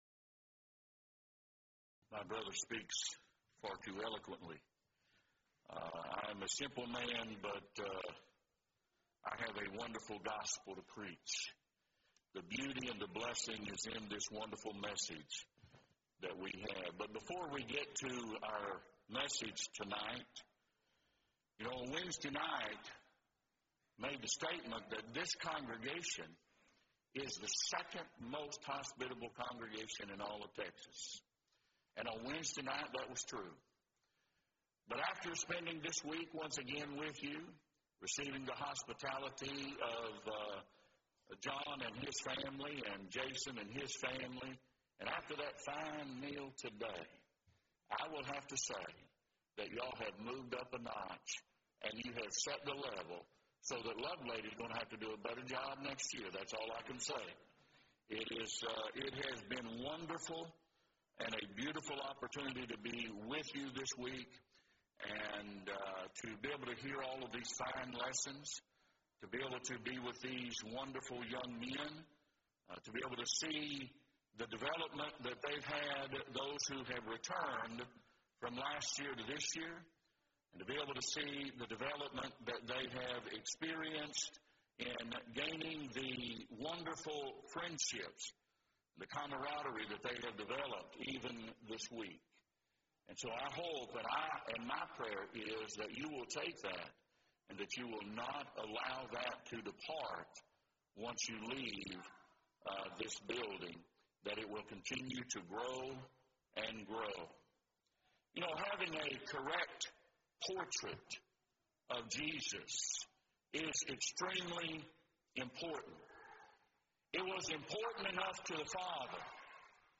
Event: 2nd Annual Young Men's Development Conference
lecture